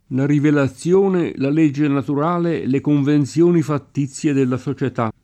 fattizio [fatt&ZZLo] agg. («artificiale; non spontaneo»); pl. m. ‑zi — es.: la rivelazione, la legge naturale, le convenzioni fattizie della società [
la rivelaZZL1ne, la l%JJe natur#le, le konvenZL1ni fatt&ZZLe della So©et#] (Beccaria); una copertina fattizia, posticcia; una raccolta fattizia di bandi e ordini — cfr. fittizio